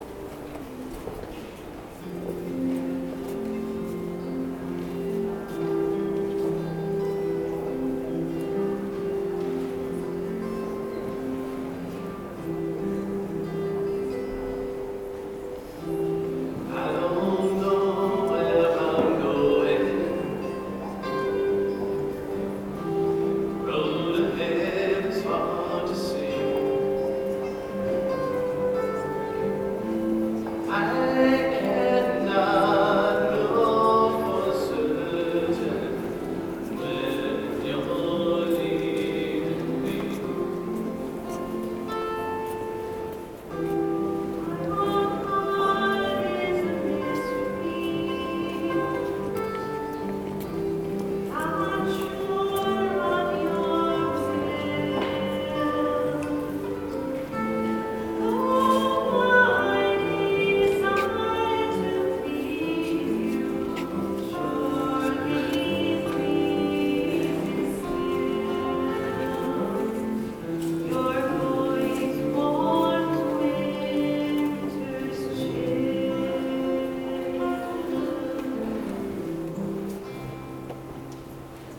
Music from the 10:30 Mass on the 1st Sunday in Lent, March 3, 2013: